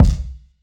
Boom-Bap Kick 98.wav